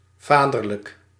Ääntäminen
Tuntematon aksentti: IPA : /pəˈtɜː(r)nəl/